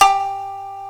Index of /90_sSampleCDs/AKAI S-Series CD-ROM Sound Library VOL-1/3056SHAMISEN